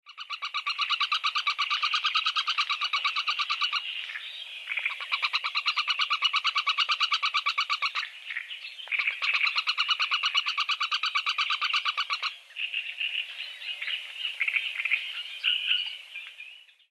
Звуки большой выпи
Вариант 2 с тревогой самки